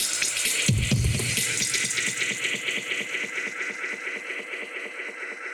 Index of /musicradar/dub-designer-samples/130bpm/Beats
DD_BeatFXB_130-03.wav